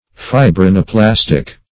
Search Result for " fibrinoplastic" : The Collaborative International Dictionary of English v.0.48: Fibrinoplastic \Fi`bri*no*plas"tic\, a. (Physiol.Chem.) Like fibrinoplastin; capable of forming fibrin when brought in contact with fibrinogen.
fibrinoplastic.mp3